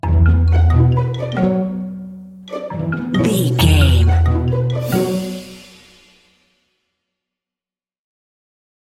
Mixolydian
percussion
strings
double bass
synthesiser
silly
circus
goofy
comical
cheerful
perky
Light hearted
quirky